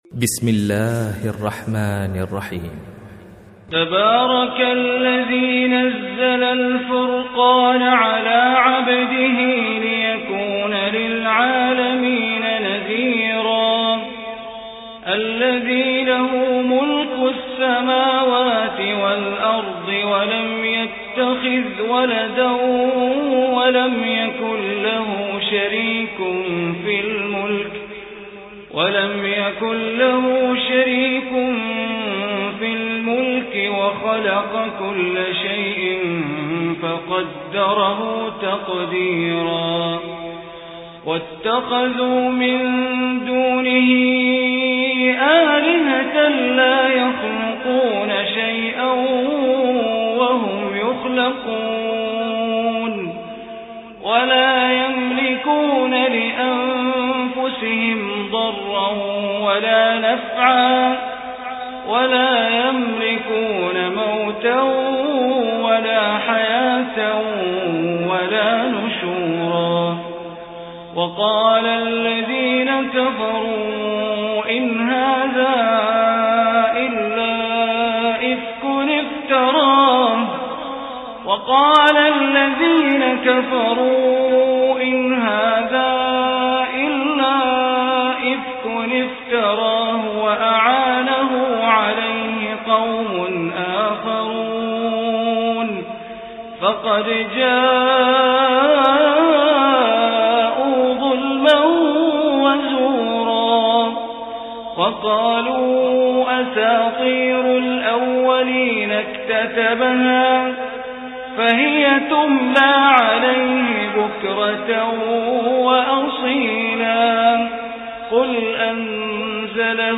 Surah Furqan MP3 Recitation by Bandar Baleela
Surah Furqan, is 25th chapter of Holy Quran. Listen online or download mp3 recitation of Surah Furqan in the beautiful voice of Sheikh Bandar Baleela.